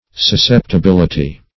Susceptibility \Sus*cep`ti*bil"i*ty\, n.; pl.